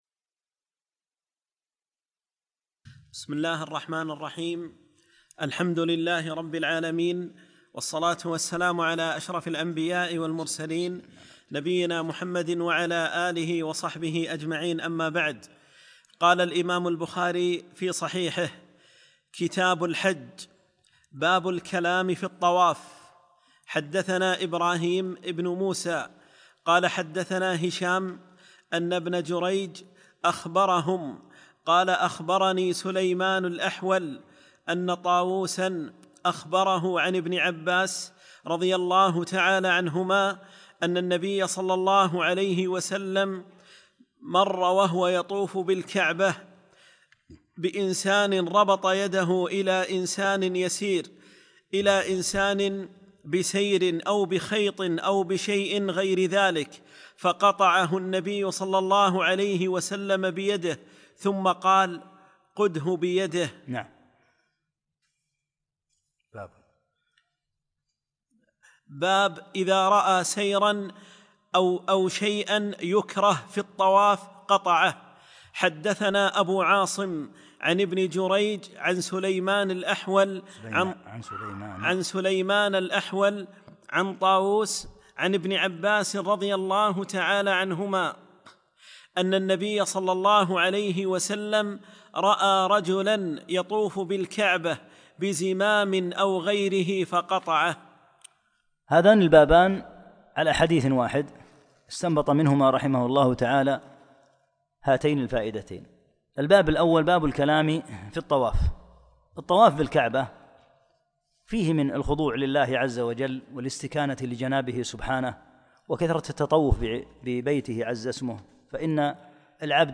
9- الدرس التاسع